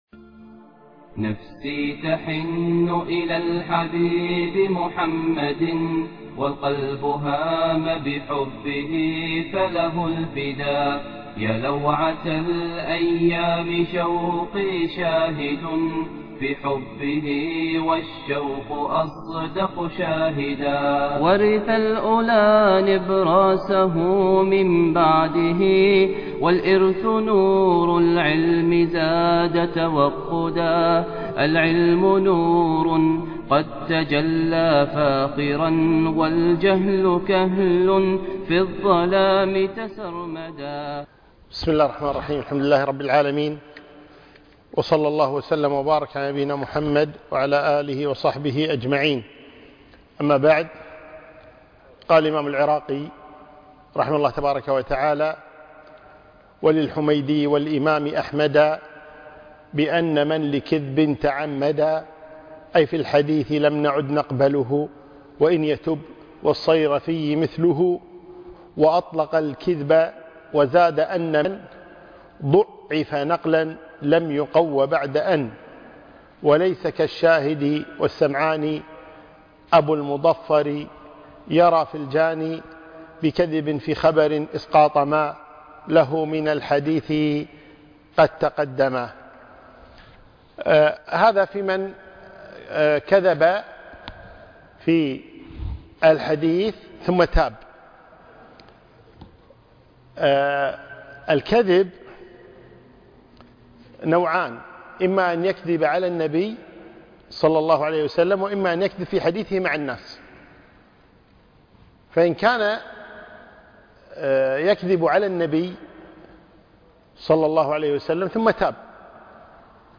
الدرس الرابع - مادة مصطلح الحديث